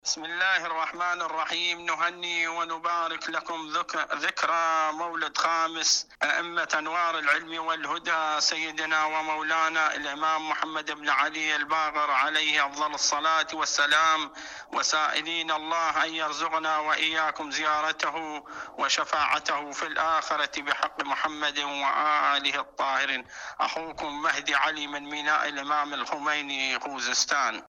إذاعة طهران- المنتدى الإذاعي